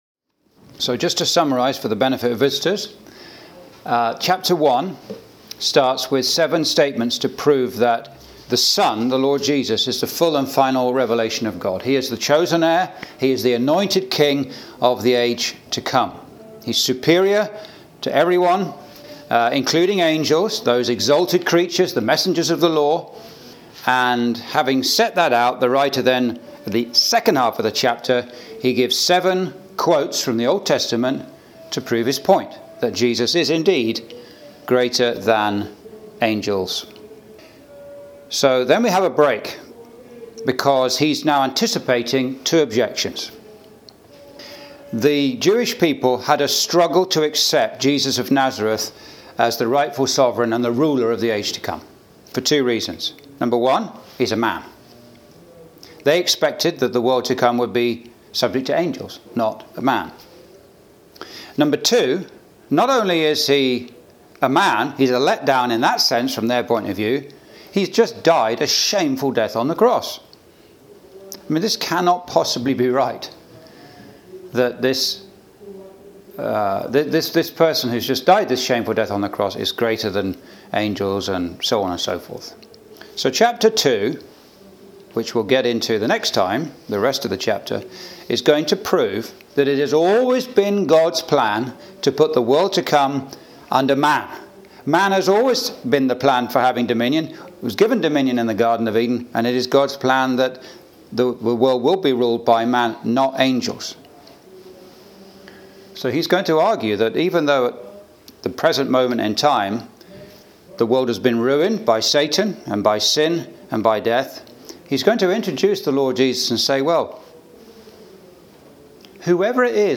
(Message preached in Chalfont St Peter Gospel Hall, 2024)
Verse by Verse Exposition